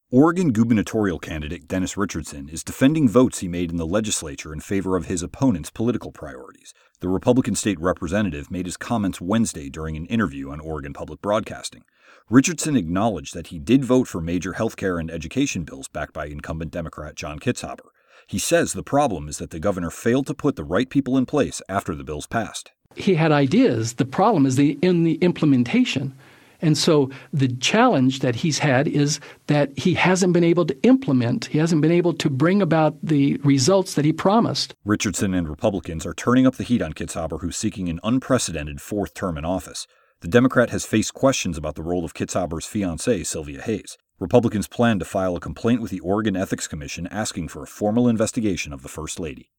The Republican state representative made his comments Wednesday during an interview on Oregon Public Broadcasting.